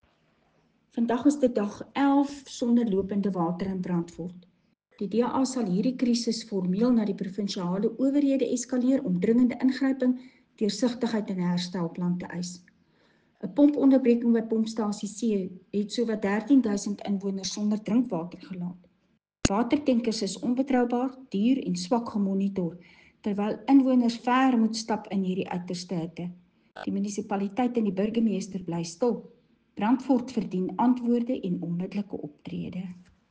Afrikaans soundbites by Cllr Marieta Visser and